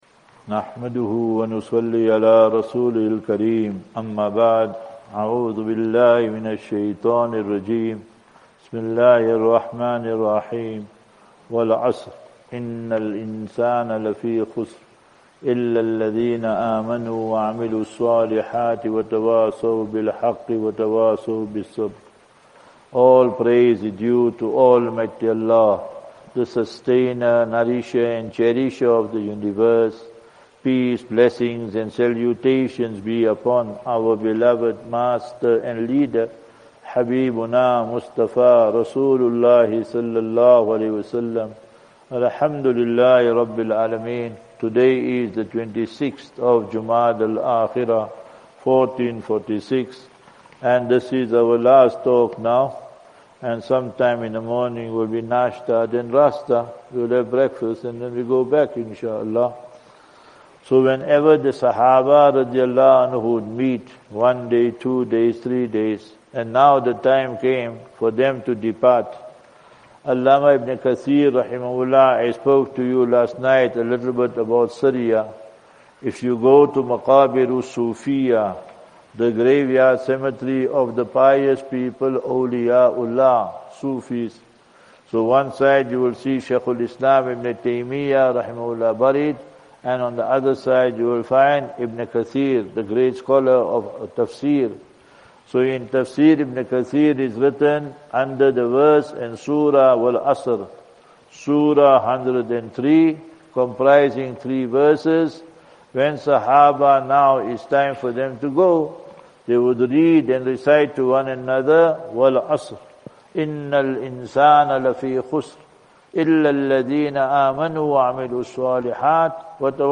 Morning Discourse